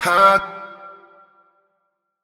TS Vox_7.wav